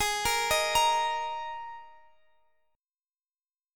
Listen to G#sus2 strummed